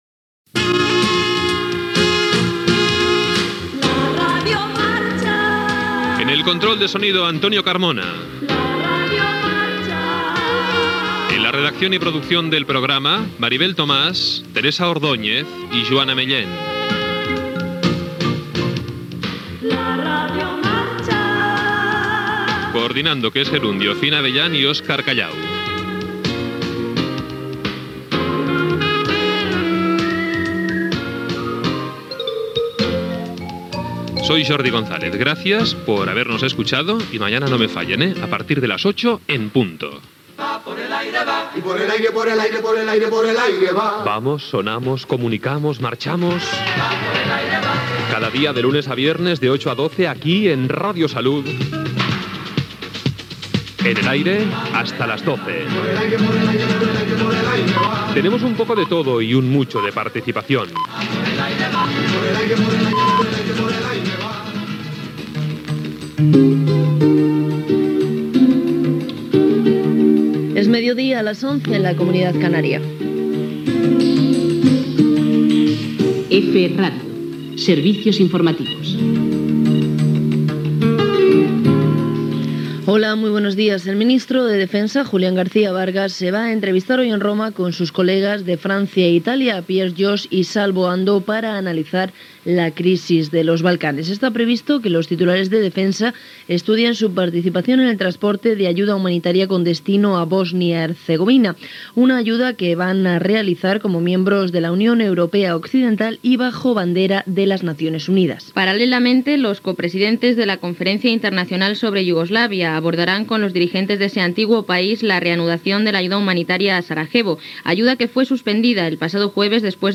78e29a81dba7f8d6086f507ed413a2bac2f673df.mp3 Títol Radio Salud Emissora Radio Salud Titularitat Privada local Nom programa La radio marcha EFE Radio Servicios Informativos La aventura Descripció Comiat del programa "La radio marcha", amb els integrants de l'equip, promoció del programa, hora, EFE Radio Servicios Informativos: ajuda humanitària a Bòsnia Hercegovina, crisis econòmica, avortament i codi penal, Israel. Indicatiu de l'emissora, publicitat, indicatiu de l'emissora. Inici del programa "La aventura" Gènere radiofònic Informatiu Entreteniment Anunciant Ortogras, Patés Morte, Joyería Palou.